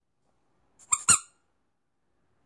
音频I " Pasosen Hielo
Tag: 环境 atmophere 记录